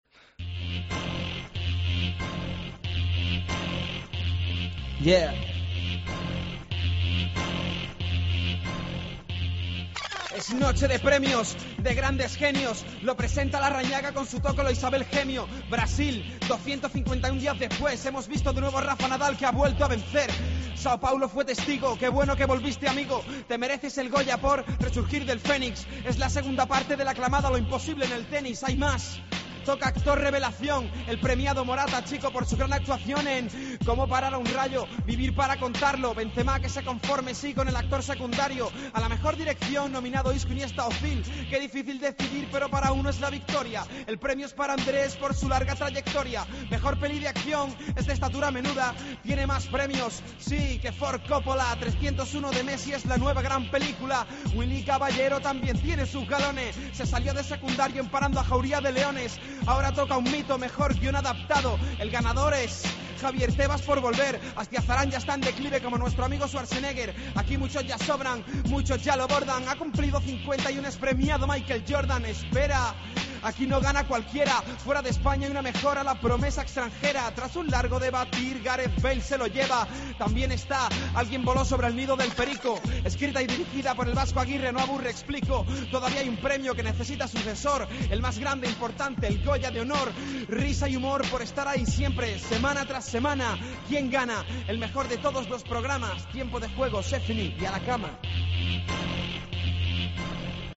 cerramos a ritmo de rap